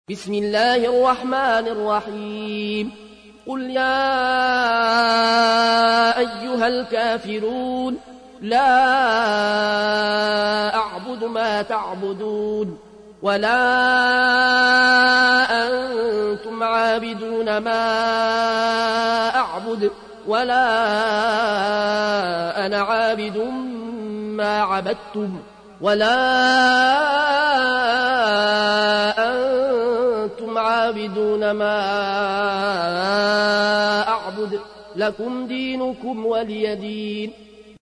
109. سورة الكافرون / القارئ